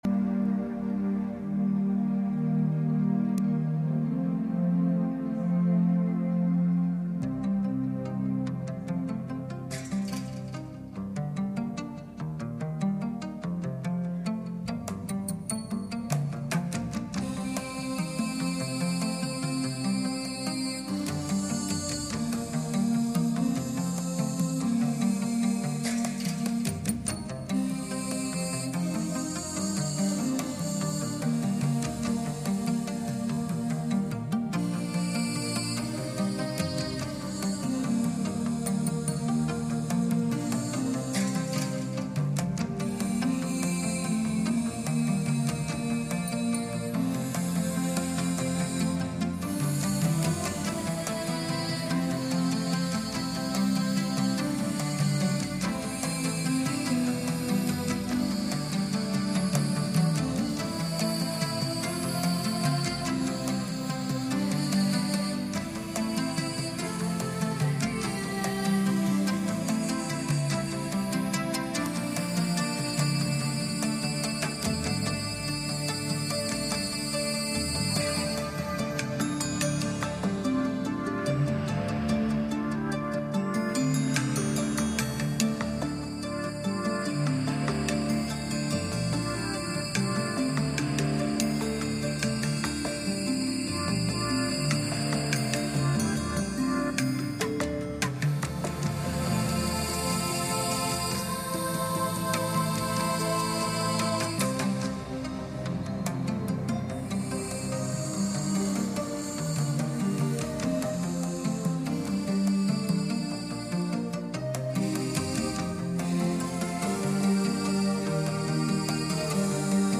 Service Type: Sunday Evening